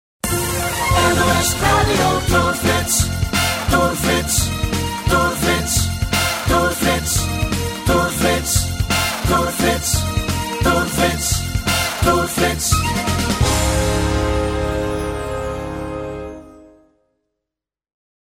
superswingende
trompet